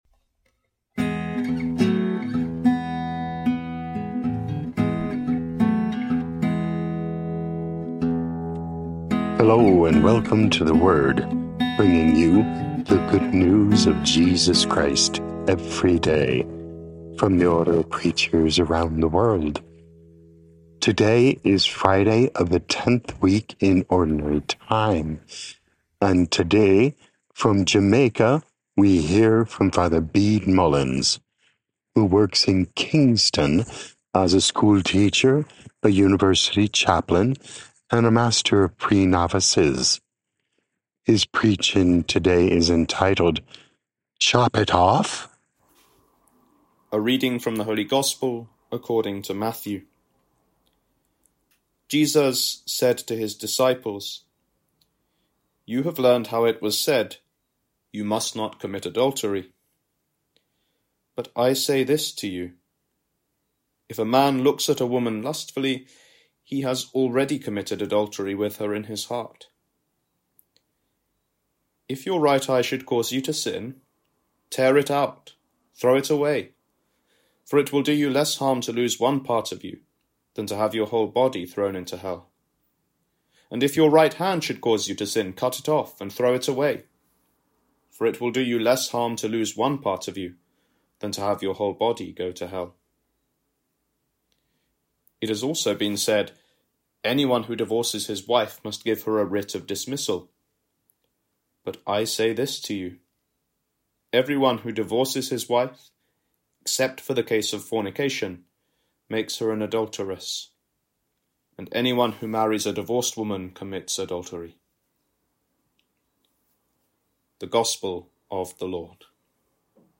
Podcast: Play in new window | Download For 13 June 2025, Friday of the Tenth Week in Ordinary Time, based on Matthew 5:27-32, sent in from Kingston, Jamaica.